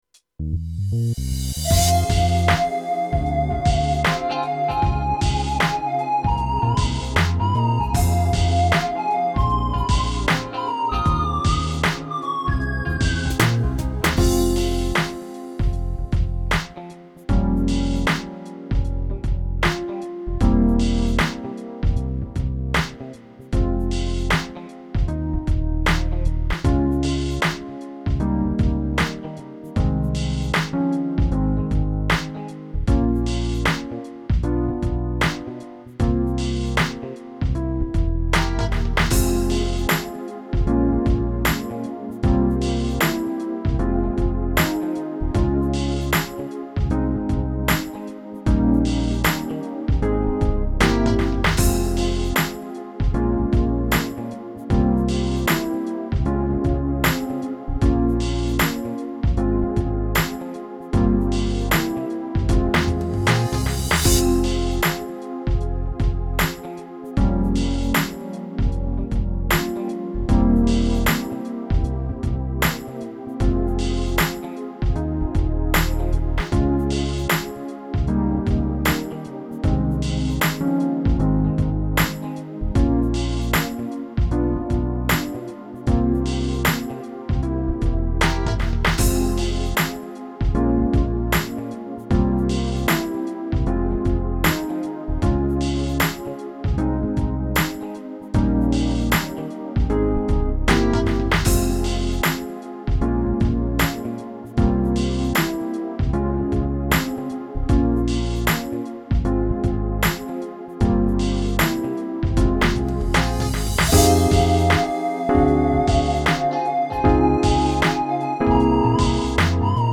караоке
Скачать минус детской песни
минусовка